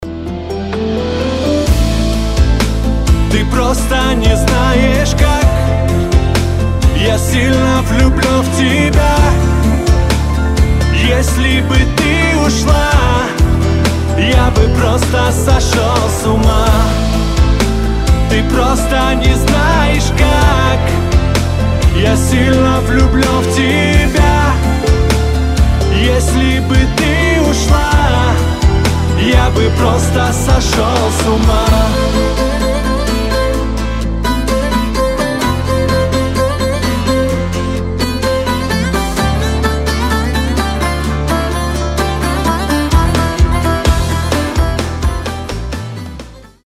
• Качество: 320, Stereo
поп
восточные мотивы
пианино